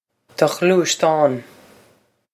Pronunciation for how to say
duh ghloo-ish-tawn
This is an approximate phonetic pronunciation of the phrase.